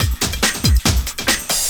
04 LOOP08 -R.wav